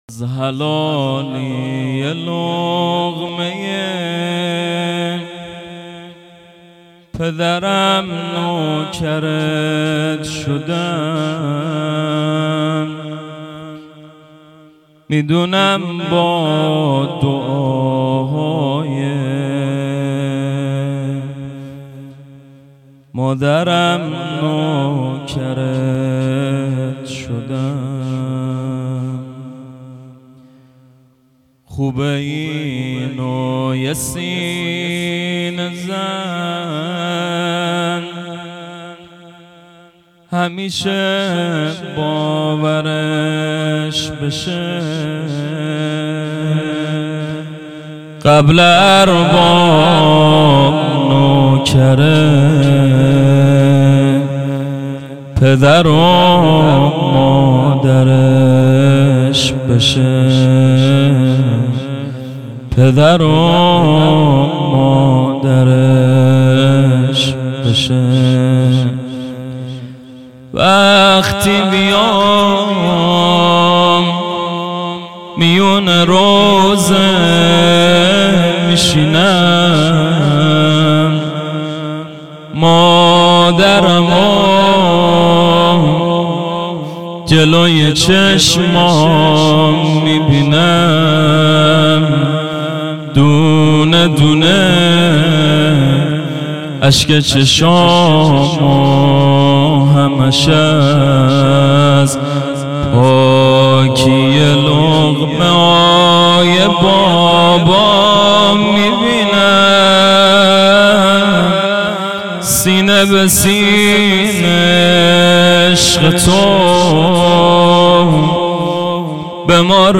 نوا